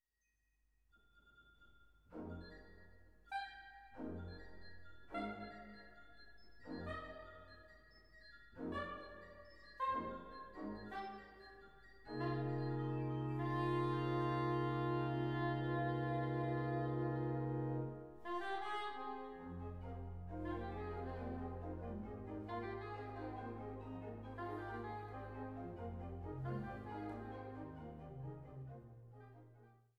Orgel
Saxophon